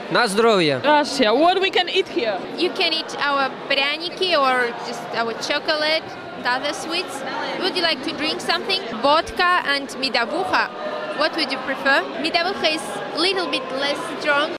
Mówią uczestnicy Forum